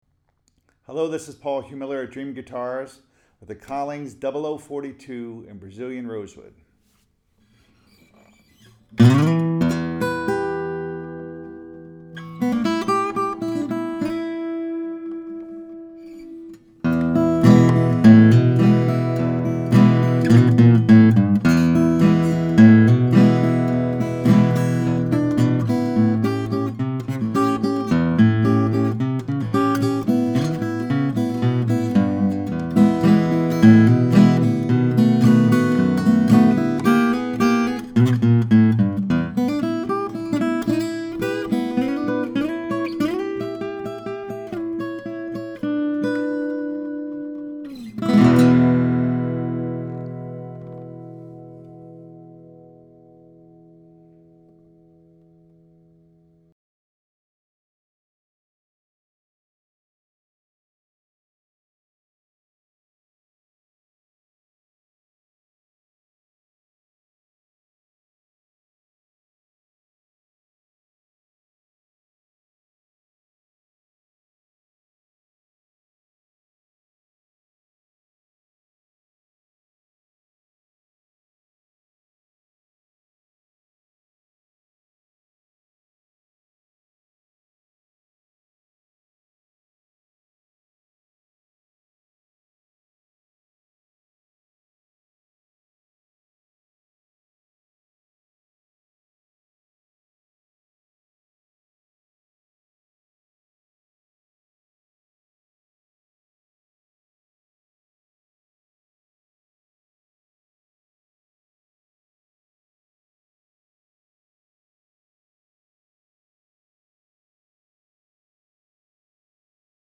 These woods most certainly help to create the magical sound of this small wonder from Austin, TX. This small body Collings speak boldly with authoritative trebles and…